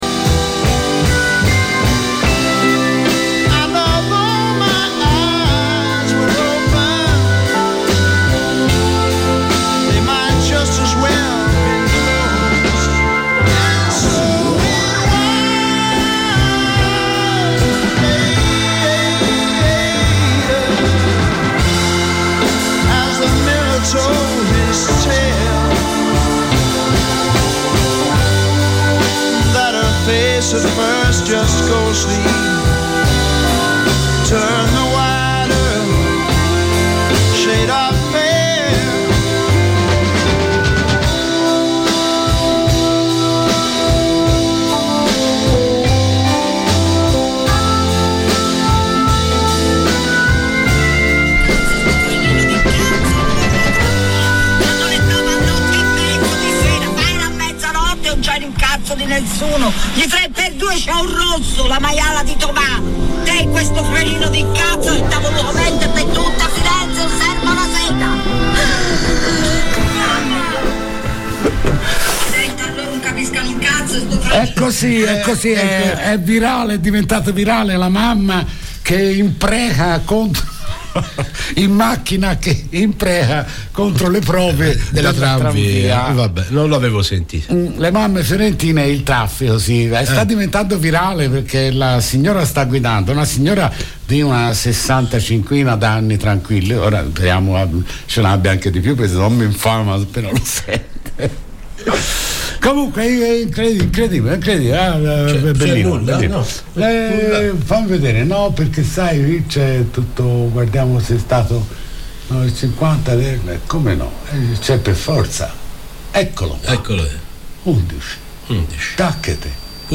Vendo compro e scambio in diretta su Controradio